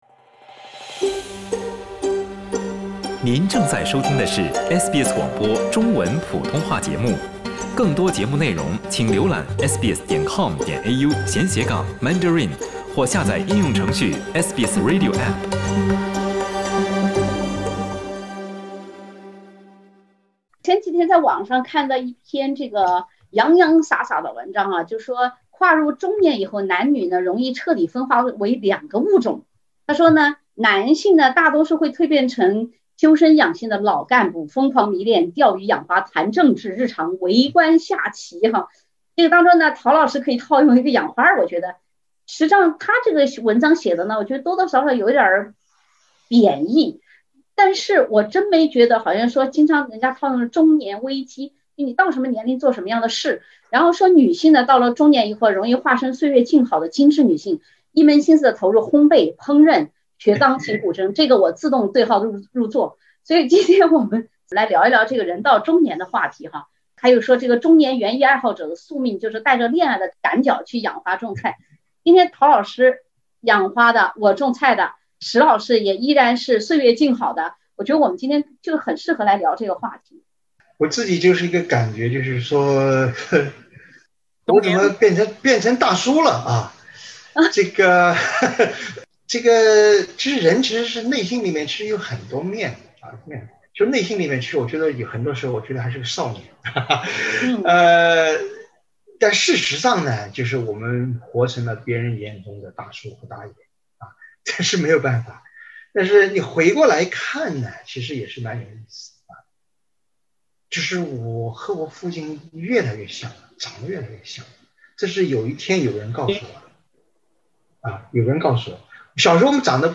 你是不是有一天突然被有的人叫做大叔和大妈了，心生万般感慨？（点击封面图片，收听完整对话）
文化苦丁茶的两位学者和主持人会不会对号入座呢？